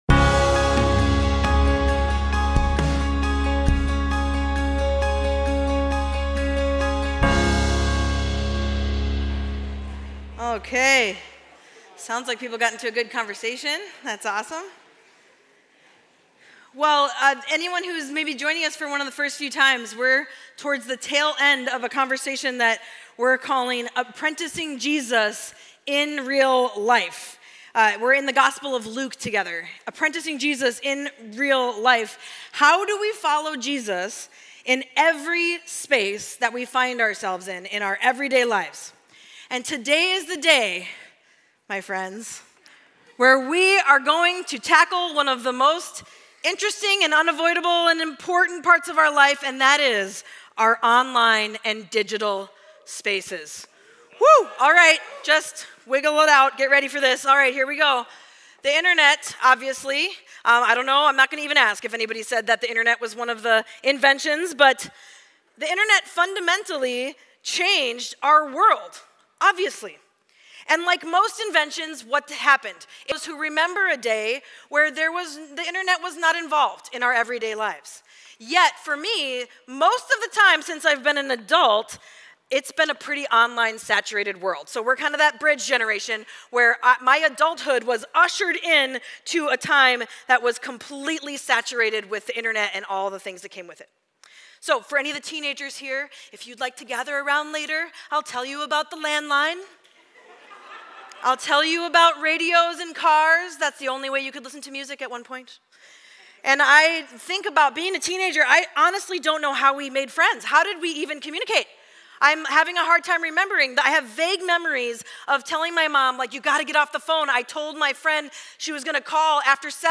Mill City Church Sermons Apprenticing Jesus (IRL): In Online and Digital Spaces Jul 22 2024 | 00:38:22 1x 00:00 / 00:38:22 Subscribe Share RSS Feed Share Link Embed